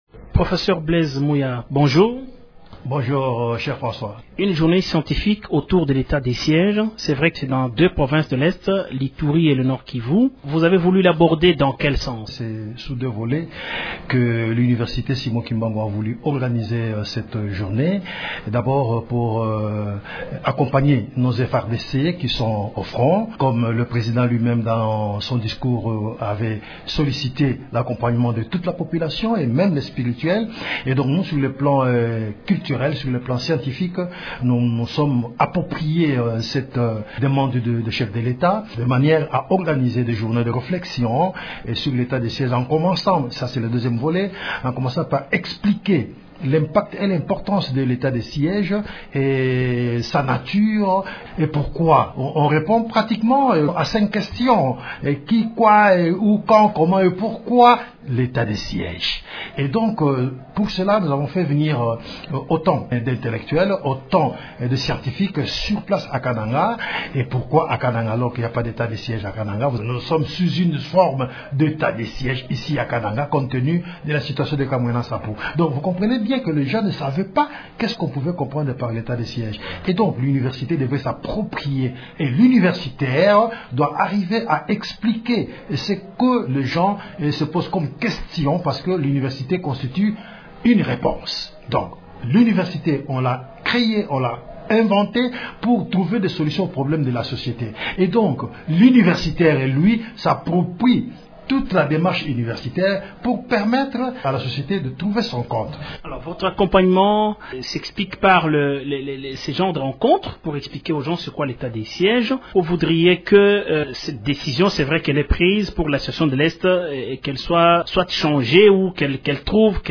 Le professeur
s’entretient avec